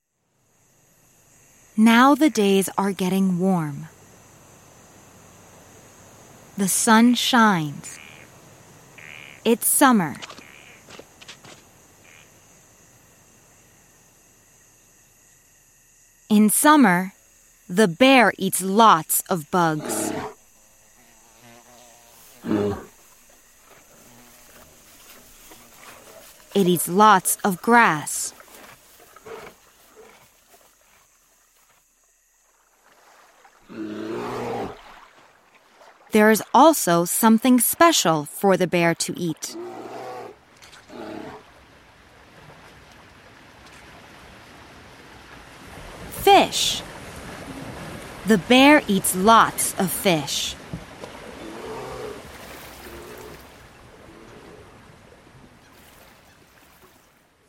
When the famed Regent Diamond is stolen, Julieta is in the middle of a high stakes mystery. With a bilingual family and international travel, the full cast portrays a range of accents, as well as phrases in Spanish and French, with panache.